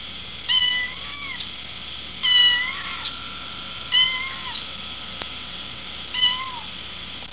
While Kat is rather vocal, Calypso doesn't meow too often, unless she smells tuna.
cat1.wav